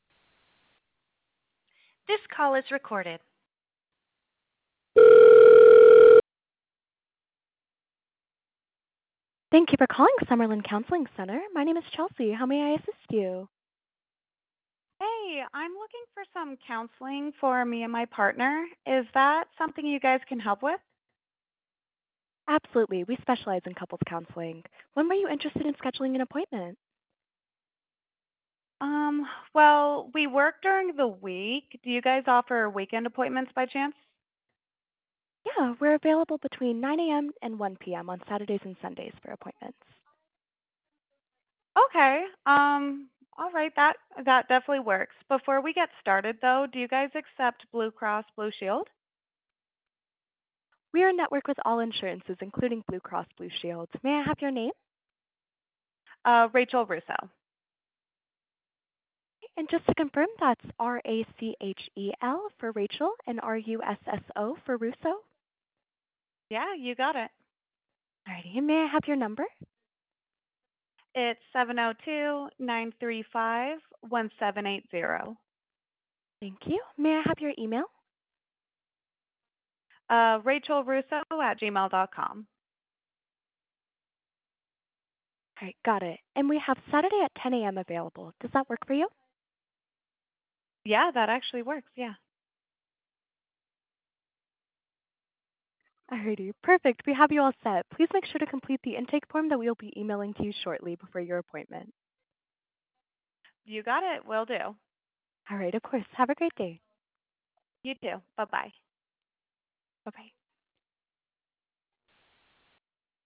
Listen to a demo to hear Abby’s virtual receptionist taking real calls like yours!
HUMAN RECEPTIONIST